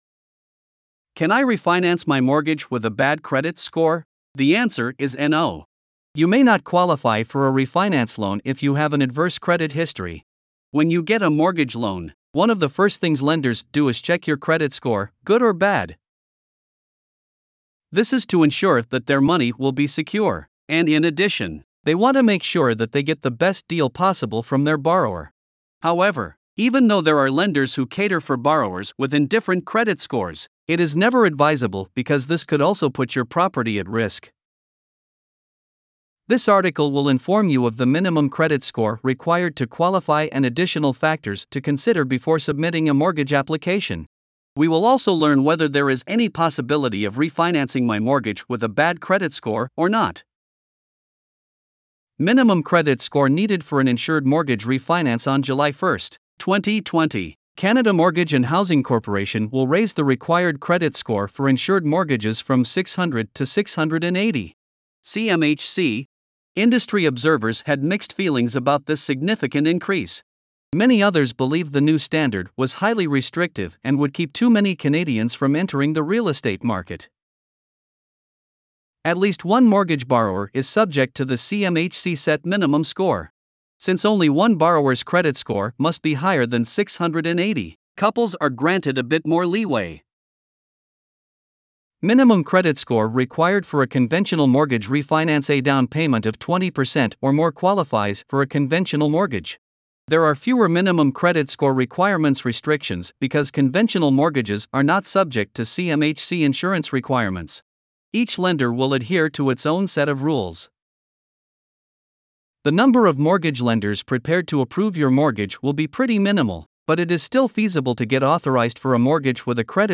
Voiceovers-Voices-by-Listnr_6.mp3